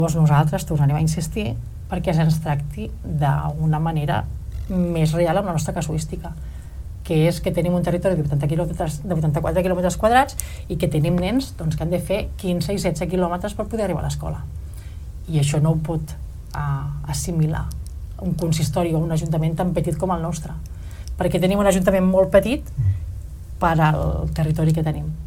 Escoltem la regidora de transports, Nàdia Cantero.